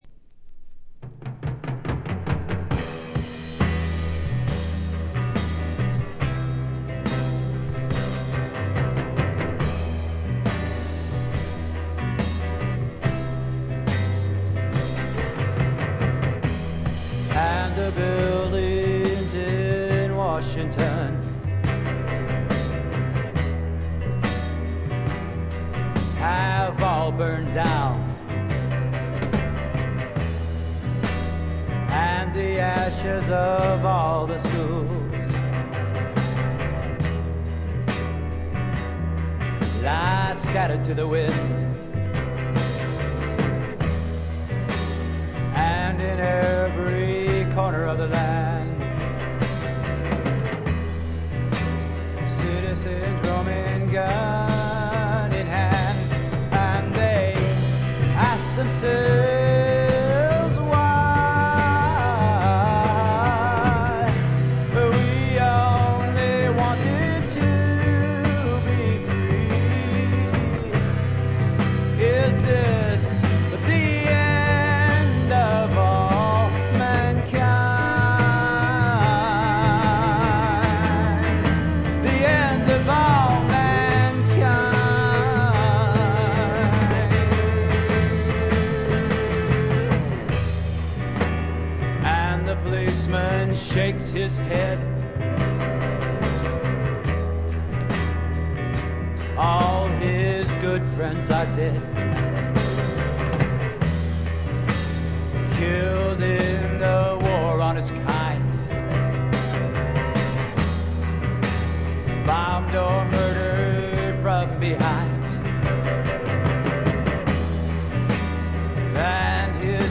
静音部での微細なチリプチ程度。
試聴曲は現品からの取り込み音源です。